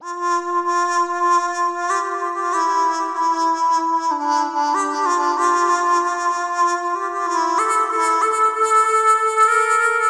有声合成器
Tag: 95 bpm Weird Loops Synth Loops 1.70 MB wav Key : F